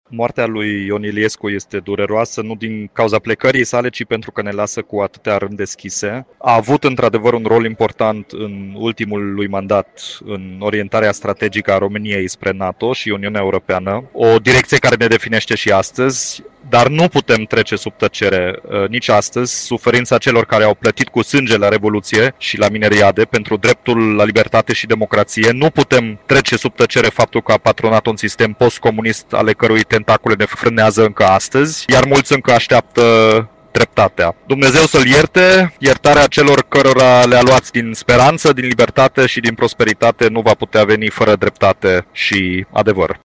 Unul dintre primii oameni politici care au transmis un mesaj după moartea lui Ion Iliescu a fost primarul Timișoarei, Dominic Fritz.
01-Dominic-Fritz-Iliescu-20.mp3